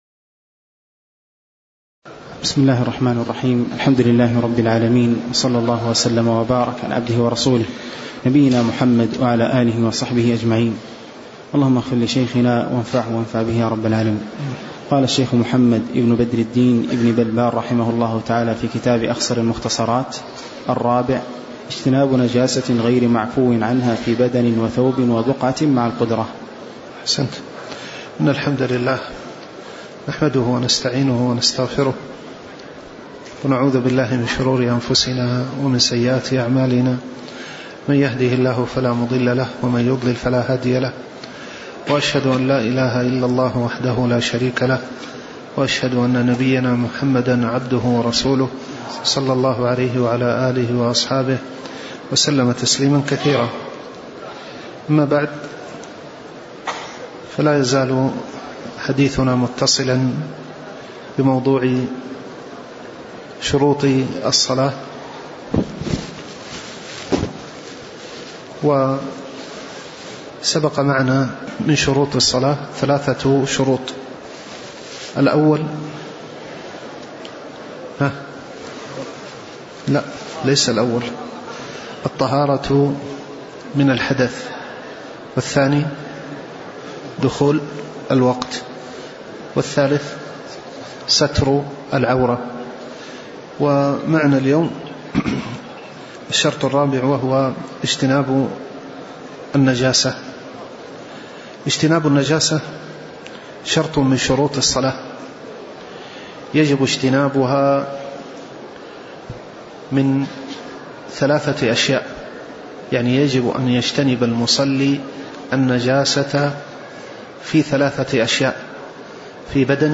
تاريخ النشر ٢٧ جمادى الأولى ١٤٣٩ هـ المكان: المسجد النبوي الشيخ